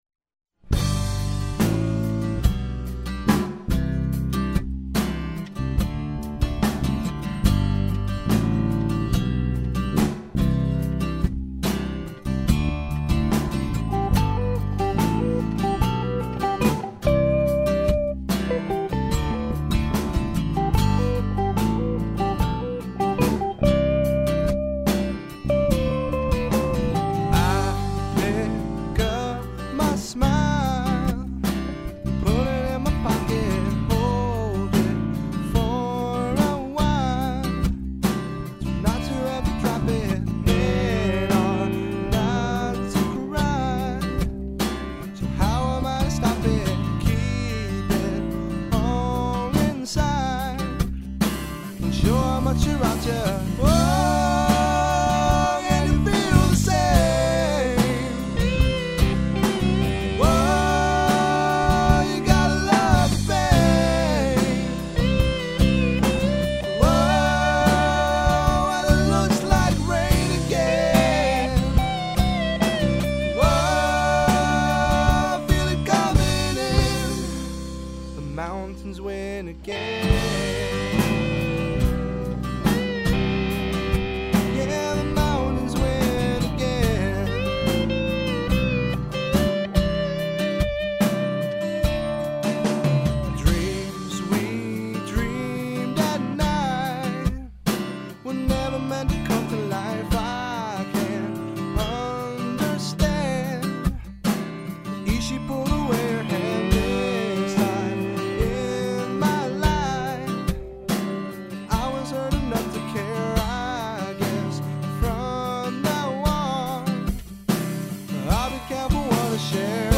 New Studio Recording
drums, lead guitar and vocals
bass and acoustic guitar
Studio Track.